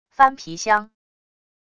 翻皮箱……wav音频